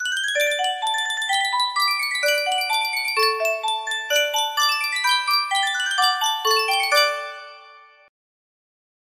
Sankyo Miniature Music Box - Put on Your Old Grey Bonnet DRX music box melody
Full range 60